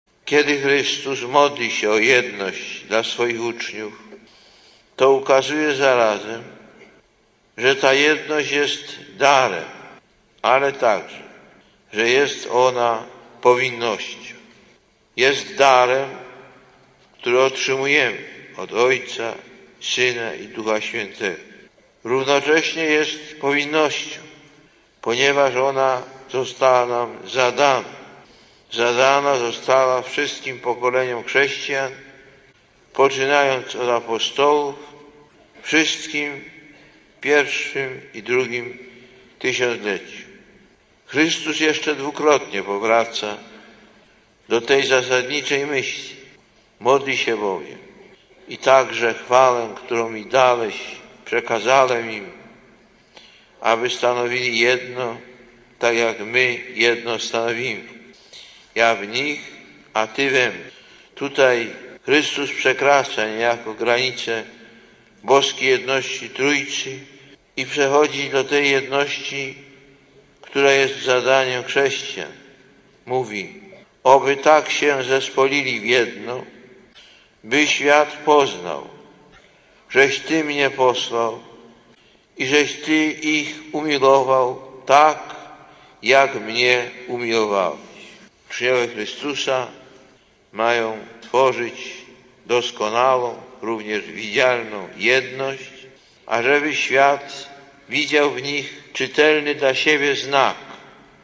Lektor: Z przemówienia podczas nabożeństwa ekumenicznego (Wrocław, 31 maja 1997 –